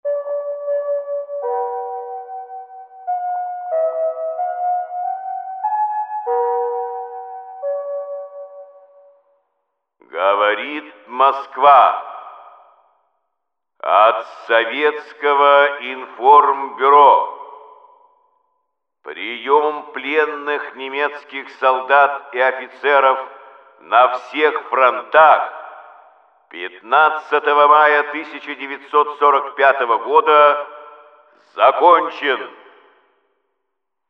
Последняя оперативная сводка от Совинформбюро вышла на московском радио 15 мая 1945 года.
15 мая 1945года.Юрий Левитан, диктор Всесоюзного радио